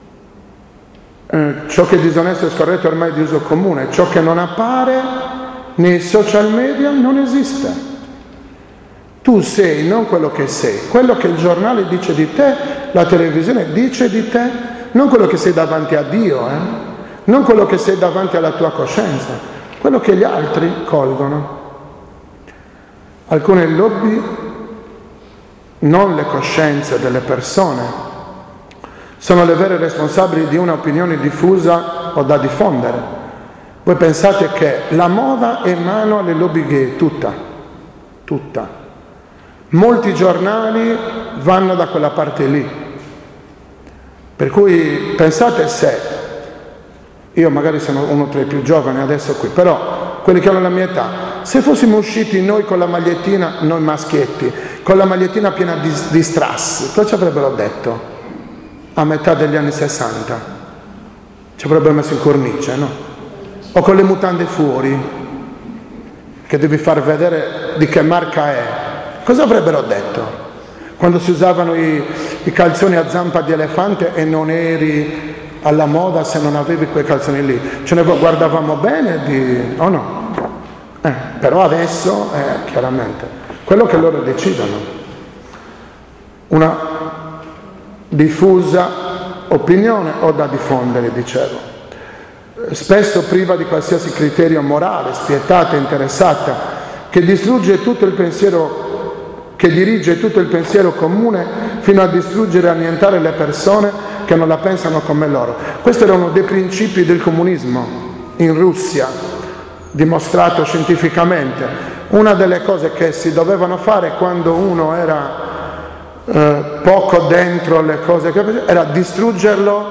2-parte-RITIRO-AVVENTO-MEDITAZIONE.mp3